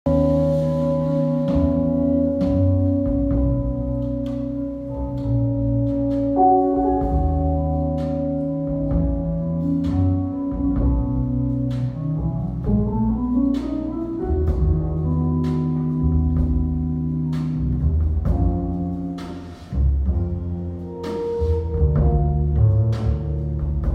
Jazzkonzert mit MÈNE – DIESEL LOKAL
Ein stetiger Zugbegleiter ist die Improvisationslust der vier Musiker.
MÈNE ist vorwärtsgerichtet, voller unkontrollierbarer Spiellaune und stellt die Weichen immer wieder neu.